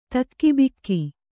♪ takkibikki